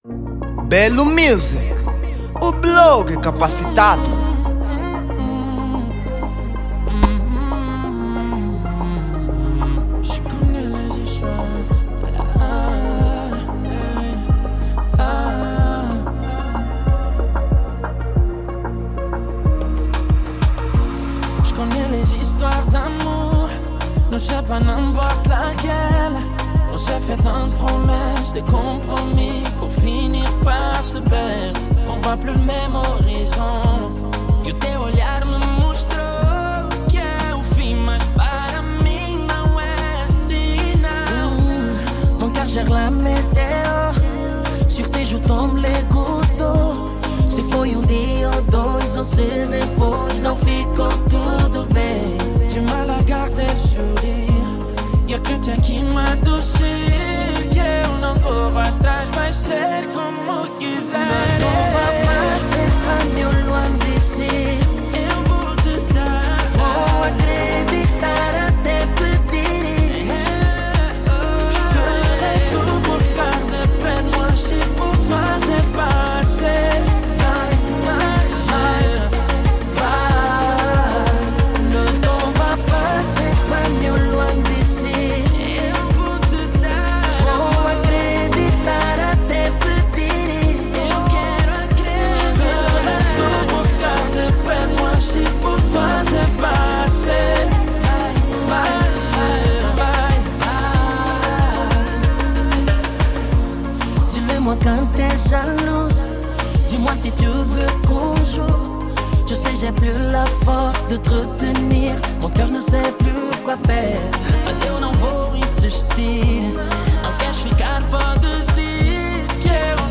Género: Kizomba